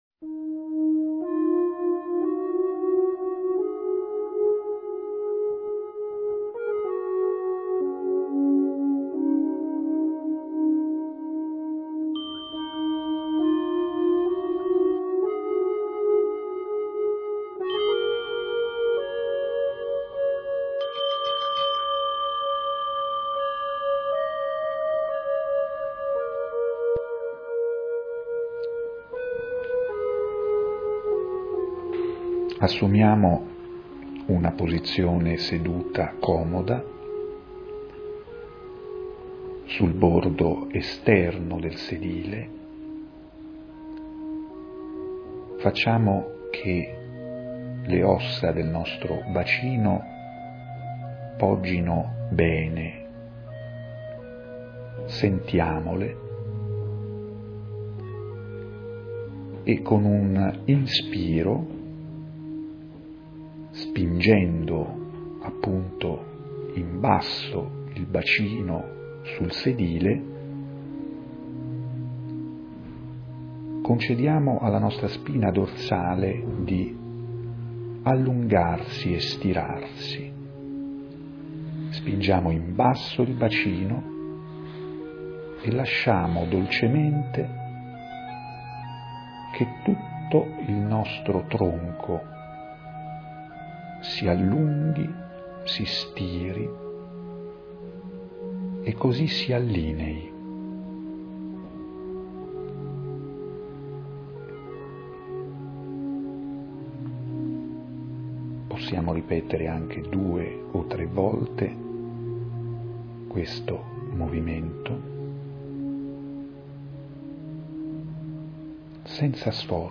Pubblichiamo insieme al video di questa pratica meditativa svolta nel corso fisico-telematico, e che riassume bene i primi passaggi della nostra meditazione, una serie di testi tratti dai commenti dei partecipanti al corso telematico di primo anno.